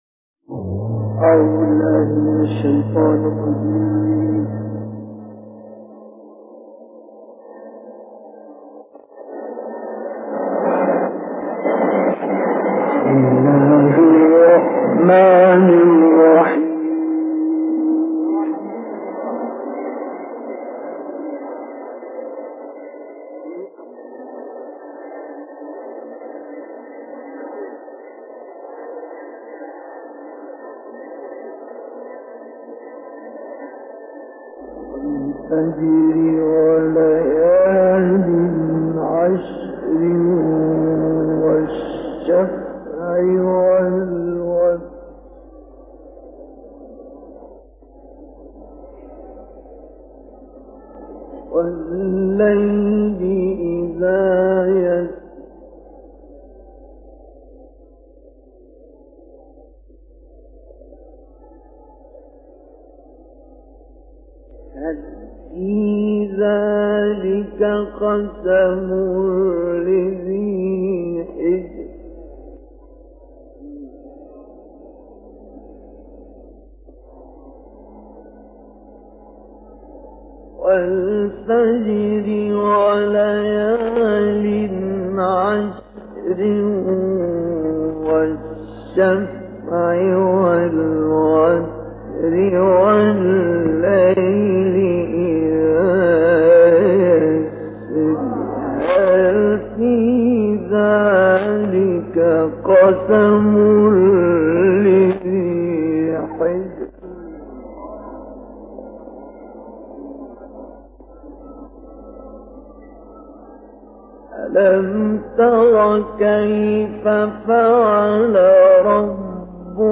تلاوت «مصطفی اسماعیل» در مسجد ابوالعلاء
به گزارش خبرگزاری بین المللی قرآن (ایکنا) تلاوت سوره‌های فجر، بلد، حاقه، نازعات و شمس با صوت مصطفی اسماعیل، قاری برجسته مصری در کانال تلگرامی این قاری بین المللی (اکبرالقراء) منتشر شده است. این تلاوت در سال 1958 میلادی در مسجد ابوالعلاء شهر قاهره اجرا شده است و مدت زمان آن 58 دقیقه است.